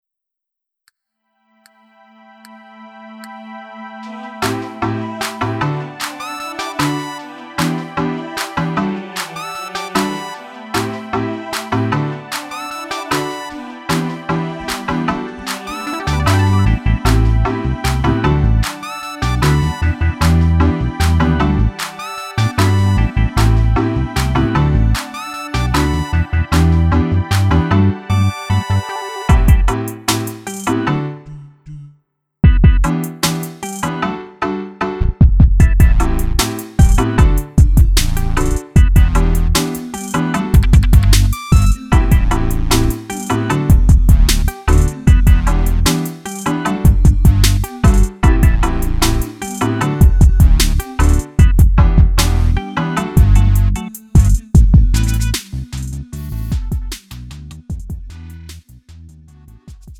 음정 -1키 2:40
장르 가요 구분